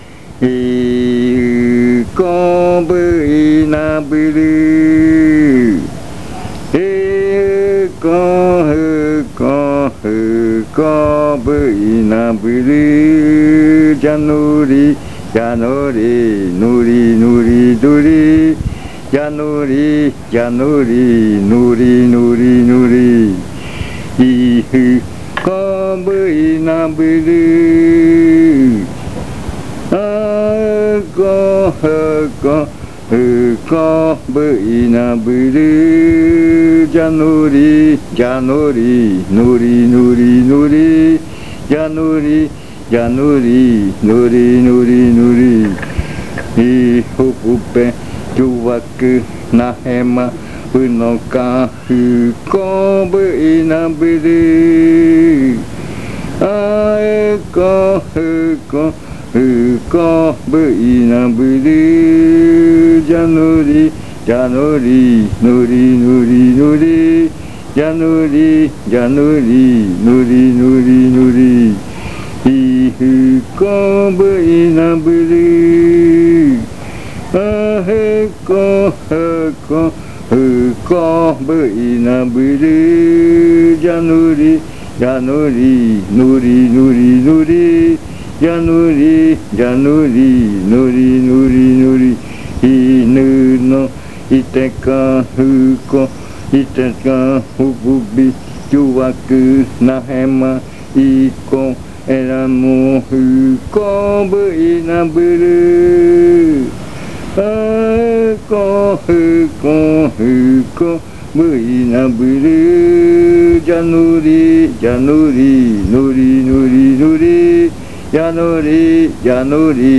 Leticia, Amazonas
Madrugada. Canto de baile de culebra, con adivinanza.
Snake dance chant, with riddle.